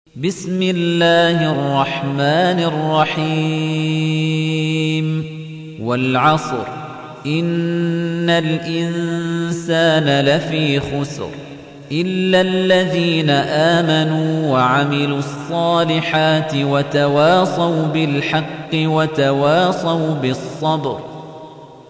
تحميل : 103. سورة العصر / القارئ خليفة الطنيجي / القرآن الكريم / موقع يا حسين